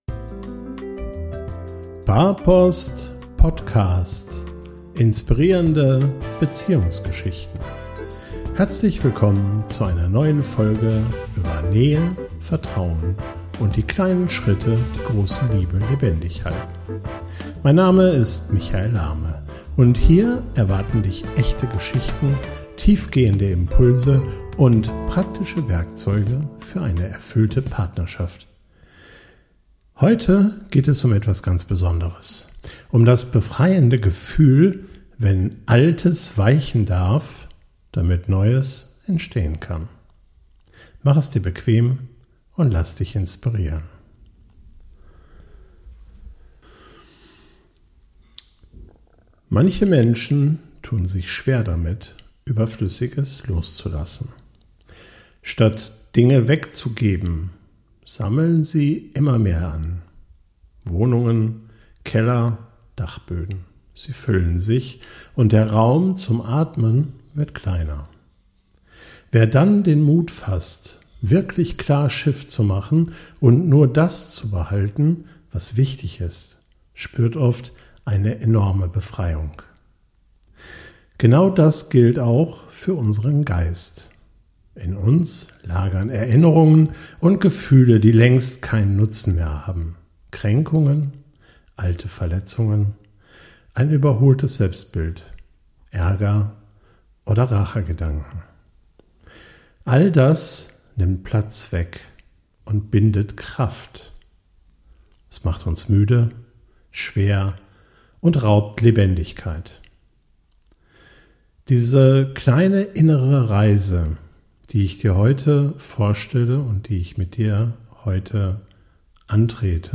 Gemeinsam gehen wir Schritt für Schritt durch eine geführte Imagination, die dir hilft, schmerzhafte Erinnerungen, einschränkende Ansichten und alte Verletzungen symbolisch über Bord zu werfen.